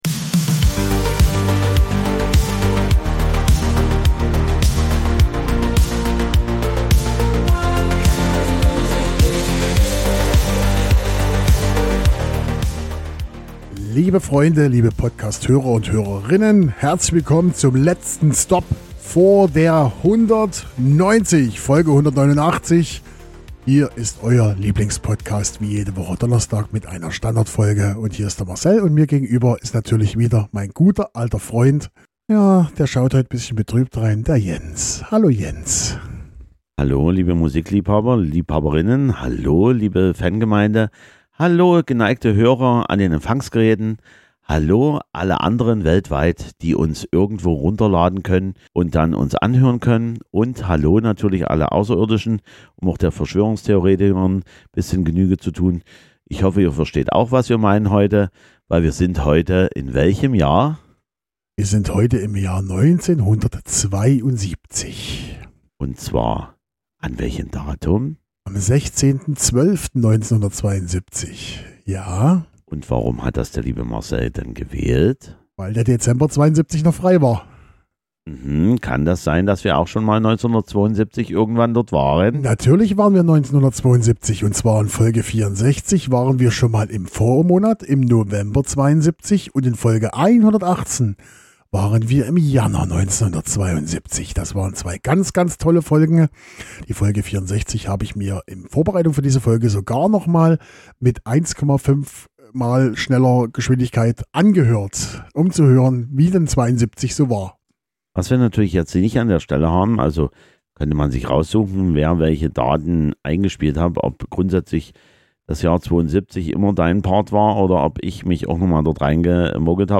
Links Folge 64 - November 1972 Folge 118 - Januar 1972 KI-Song zu dieser Folge Credits Podcastintro/-outro by Suno Hosted on Acast.